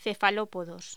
Locución: Cefalópodos
voz